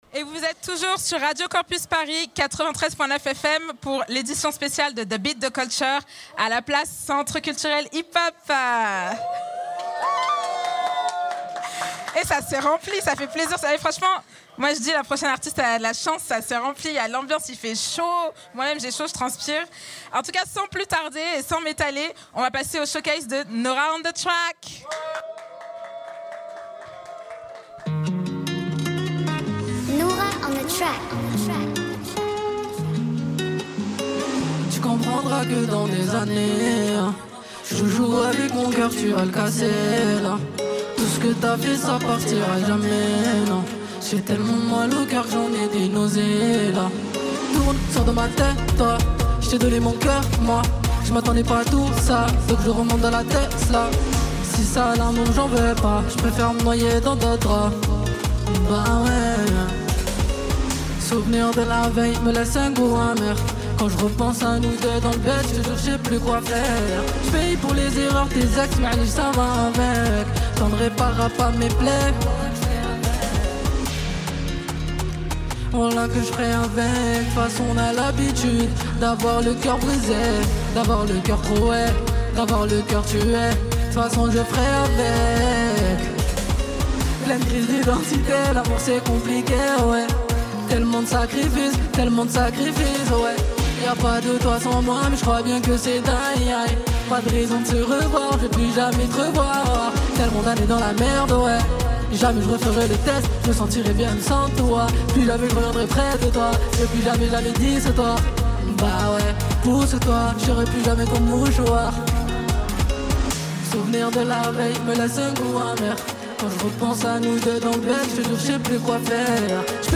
Hip-hop
passant de la mélancolie à une énergie débordante.